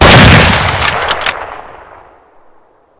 shoot_2.ogg